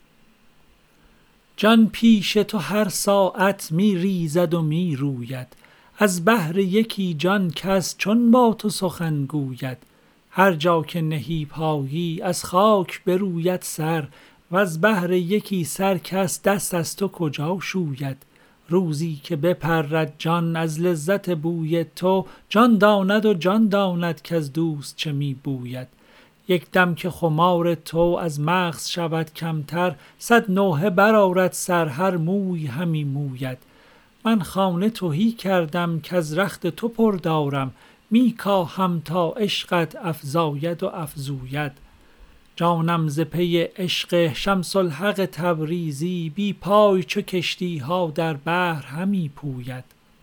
مولانا دیوان شمس » غزلیات غزل شمارهٔ ۶۲۲ به خوانش